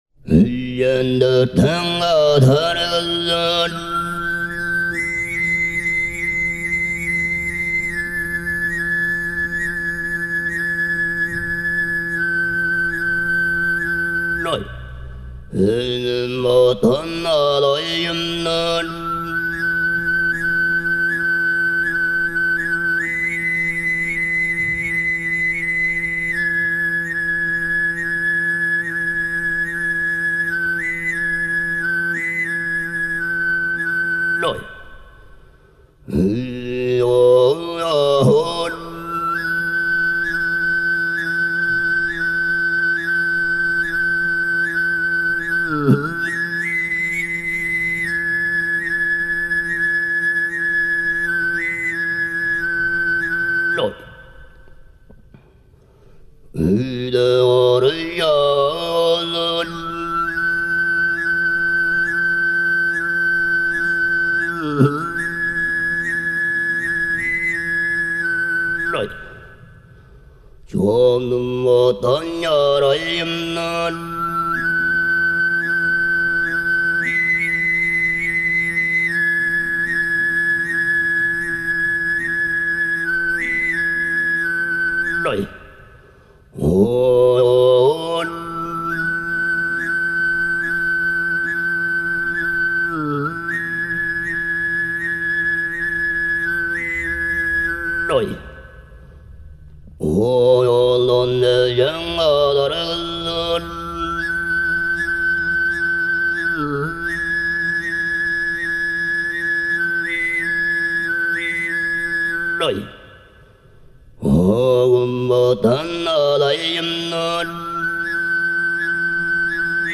тувинское народное пение Исполняет
(пение, дошпулур)